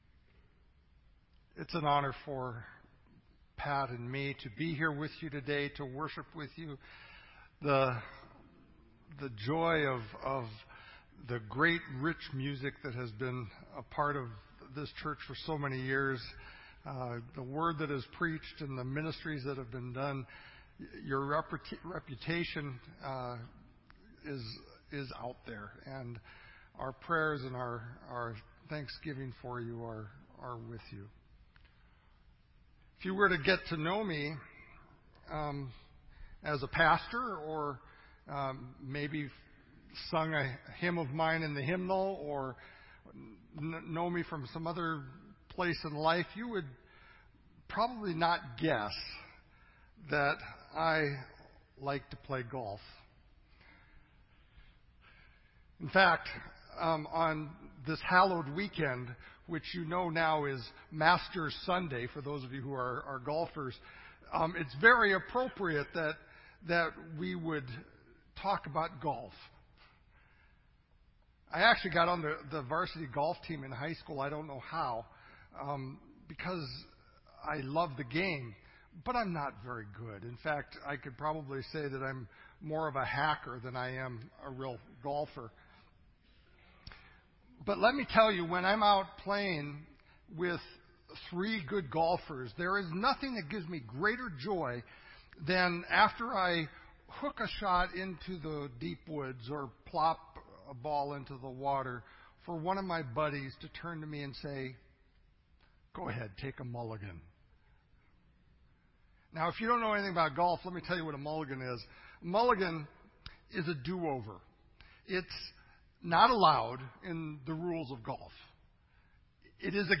This entry was posted in Sermon Audio on April 11